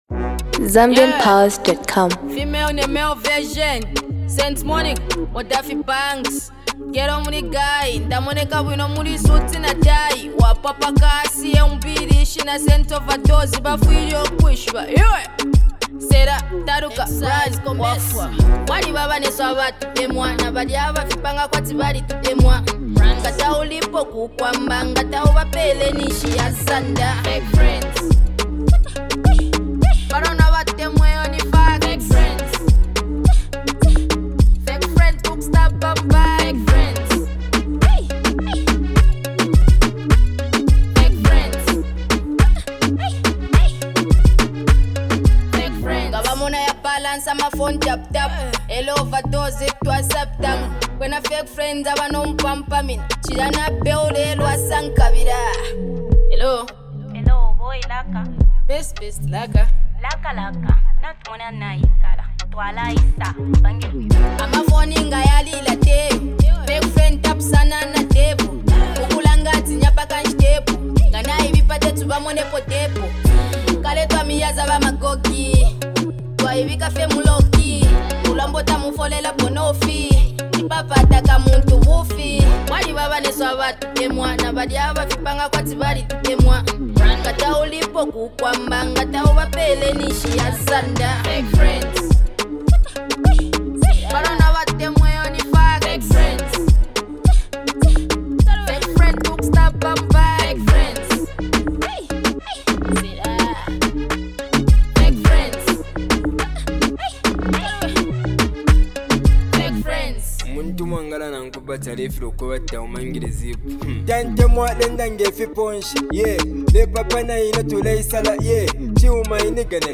emerging rapper